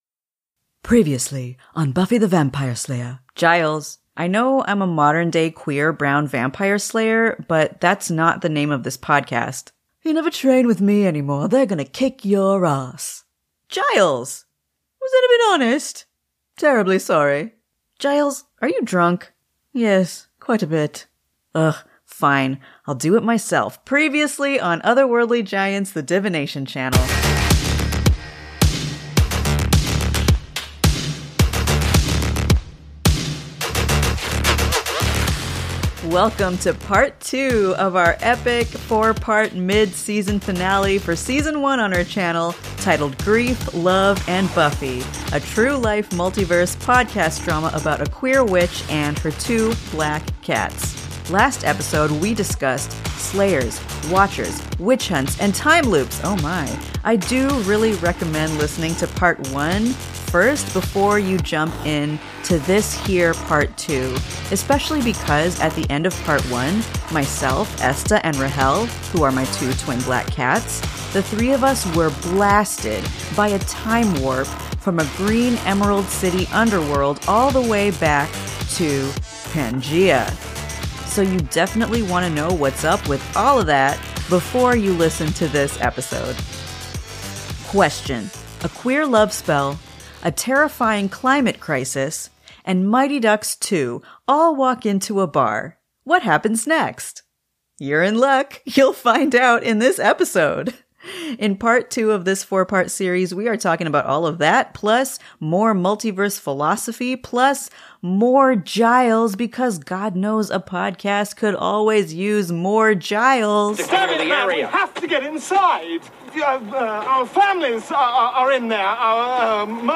This is a True-Life Multiverse podcast drama about a queer wi…